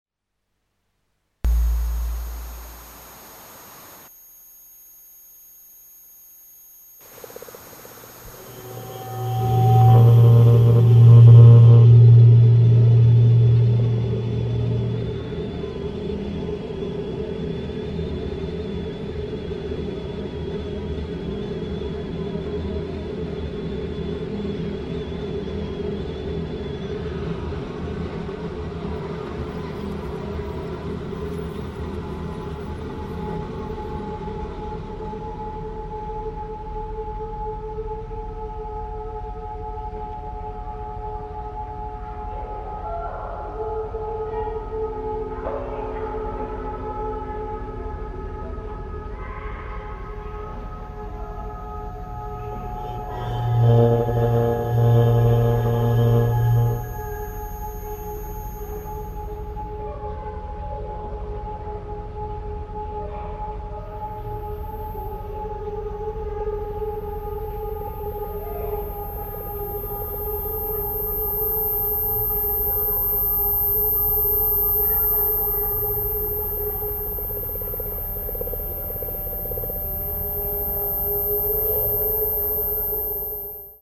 patiently unfolding electro-acoustic constructions